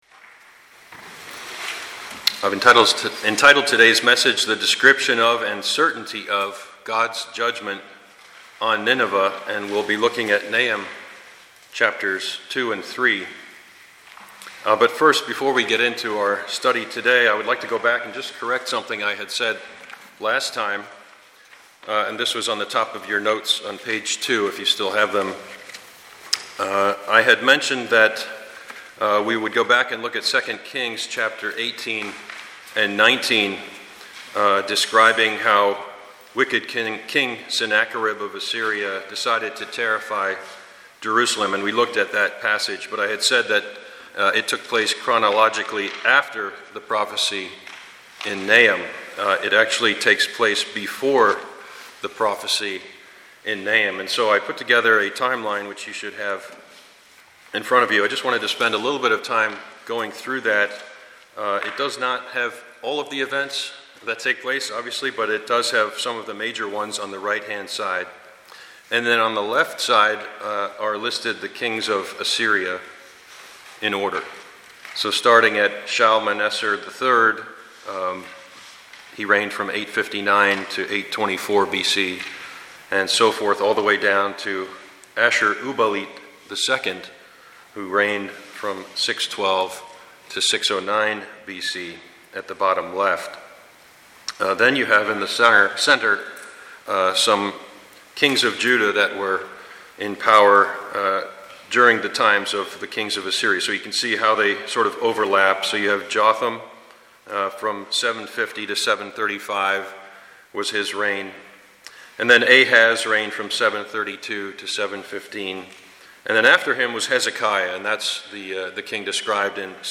Passage: Nahum 2-3 Service Type: Sunday morning